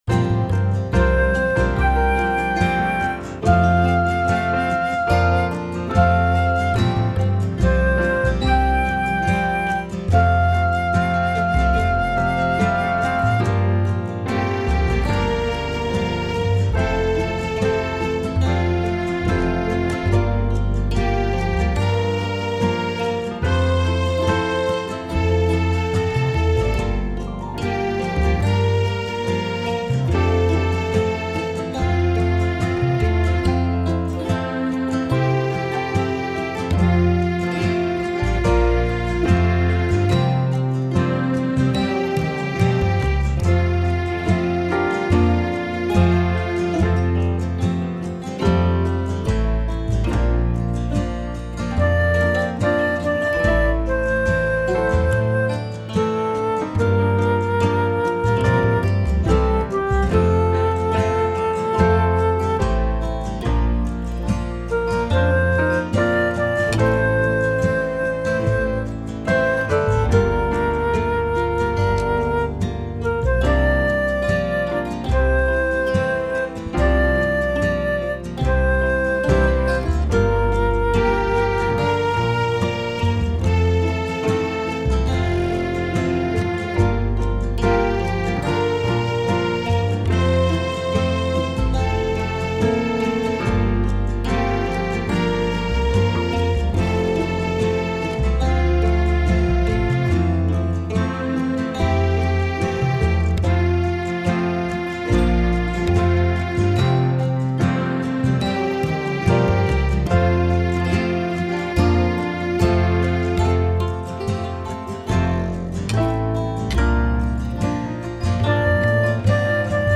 This is a touching Christmas song